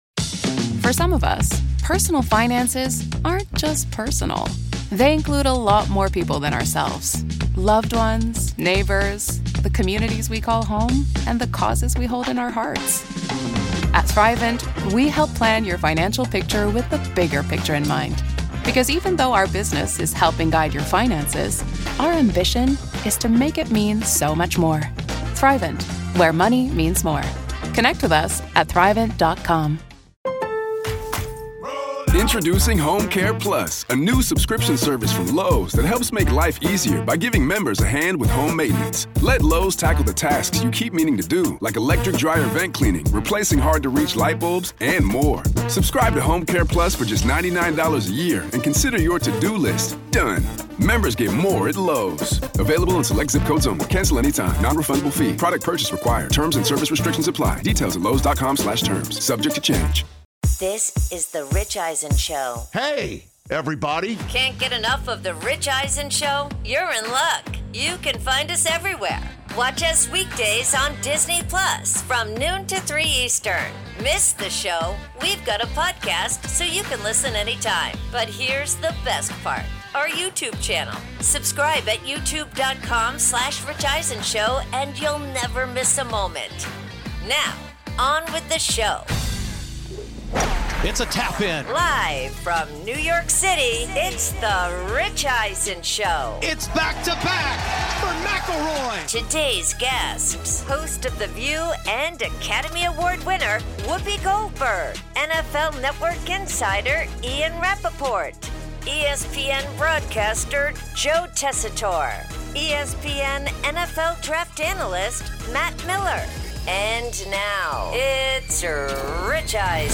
Hour 1: Rory McIlroy Wins Back-to-Back Masters, plus Whoopi Goldberg In-Studio